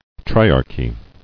[tri·ar·chy]